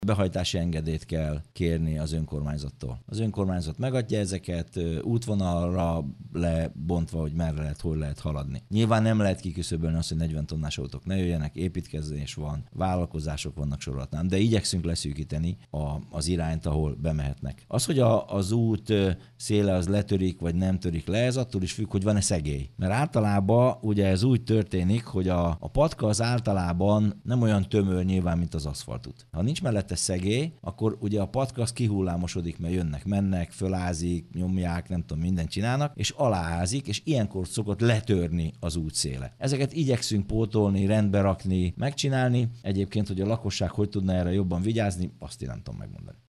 Pápai Mihály polgármestert hallják.